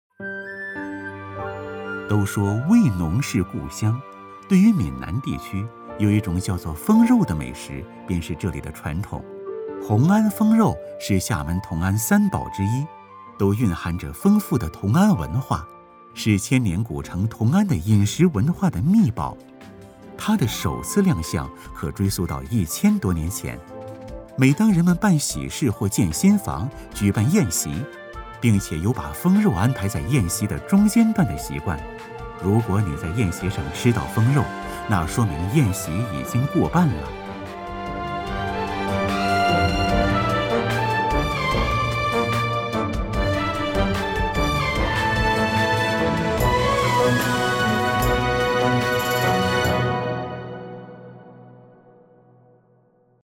舌尖-男4-同安封肉.mp3